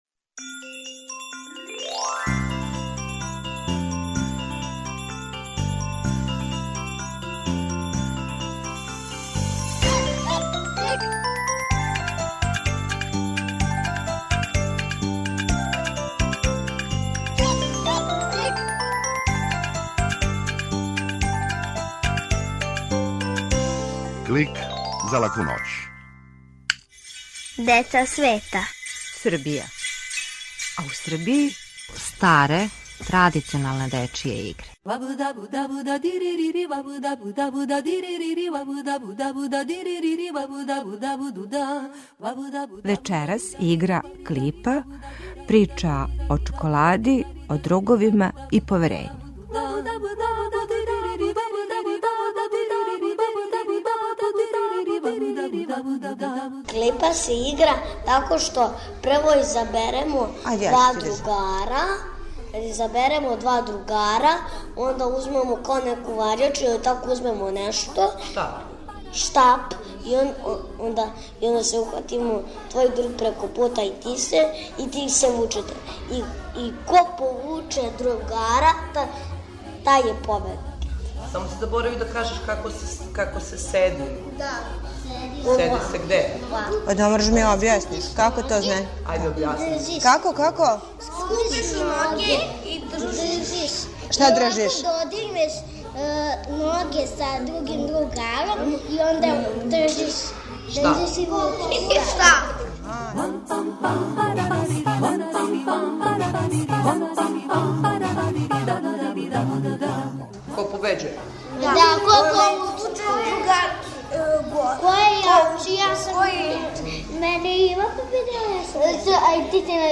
Клик је кратка емисија за децу, забавног и едукативног садржаја. Сваке седмице наши најмлађи могу чути причу о деци света, причу из шуме, музичку упознавалицу, митолошки лексикон и азбуку звука. Уколико желите да Клик снимите на CD или рачунар, једном недељно,на овој локацији можете пронаћи компилацију емисија из претходне недеље, које су одвојене кратким паузама.